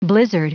Prononciation du mot blizzard en anglais (fichier audio)
Prononciation du mot : blizzard